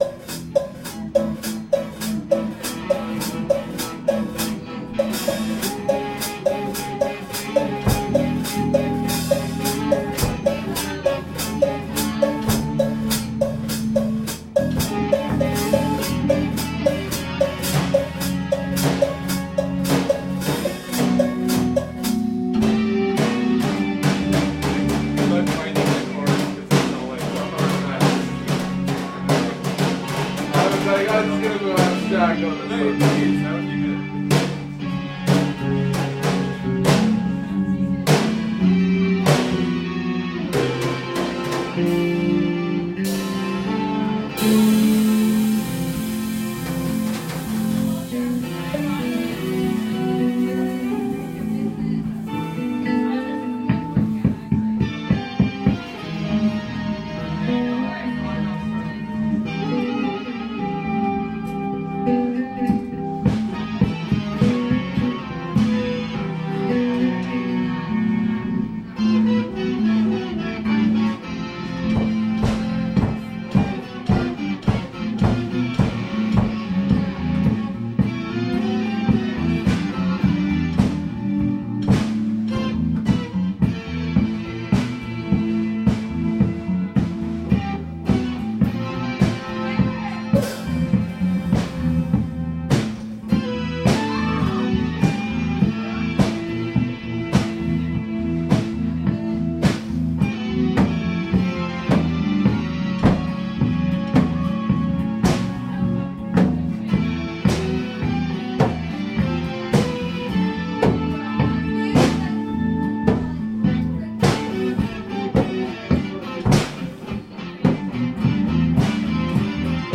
01-Nice-intro-harmonized-guitar [ 2:19 ] Play Now | Play in Popup | Download
01-Nice-intro-harmonized-guitar.mp3